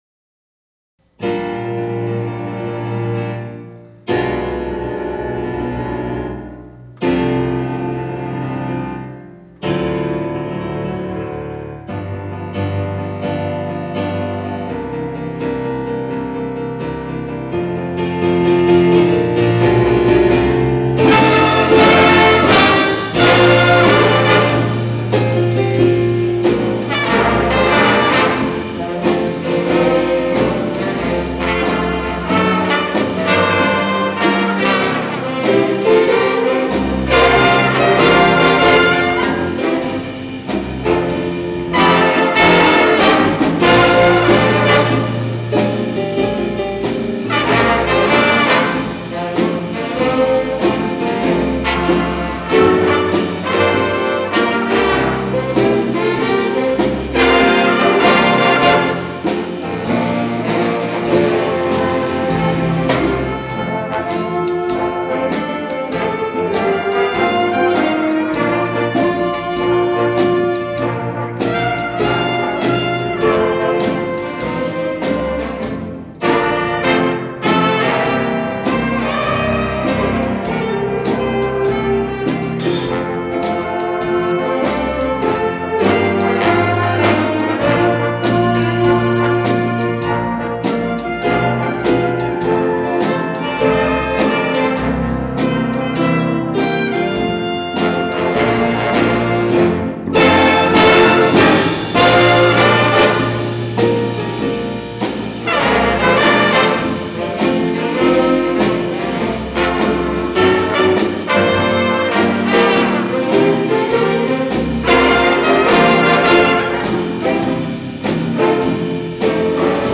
Jazz Concert 2025 Recordings